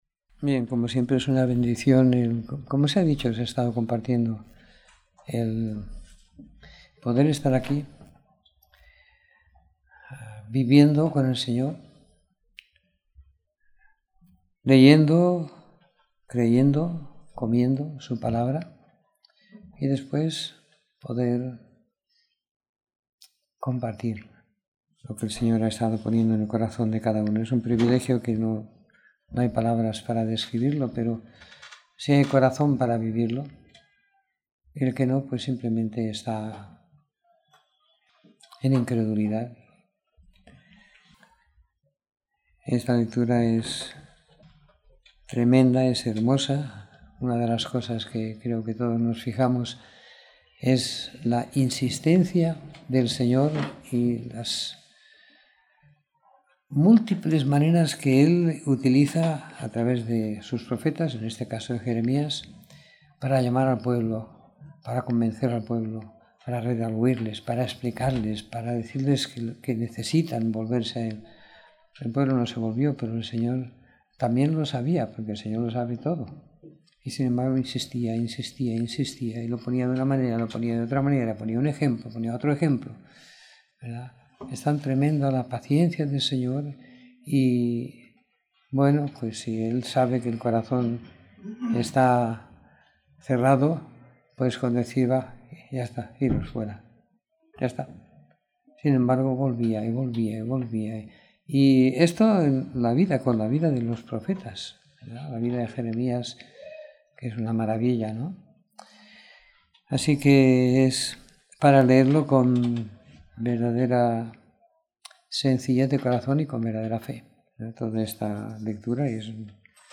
Comentario en Jeremías 31 - Lamentaciones 5